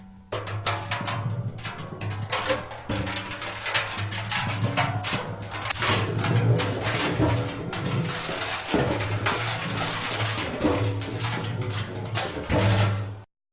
Звуки бочки
Еще вариант, где катят бочку